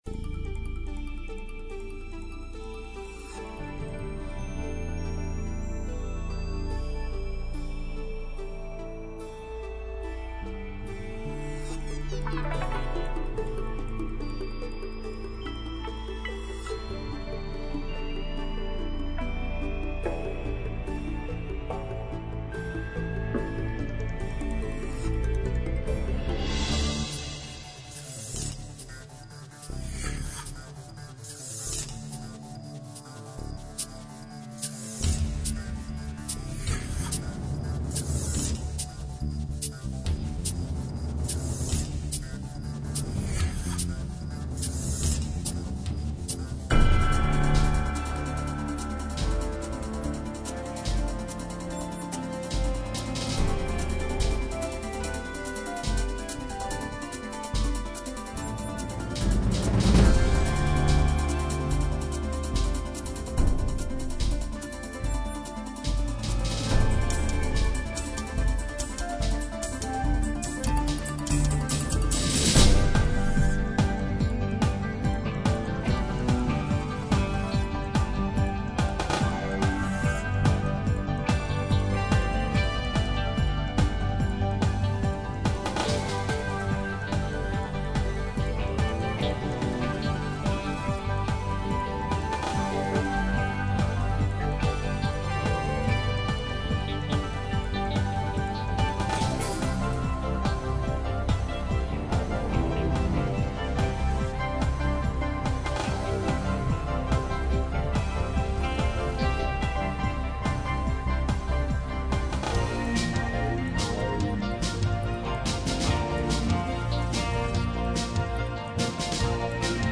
Connect Now (music for animation).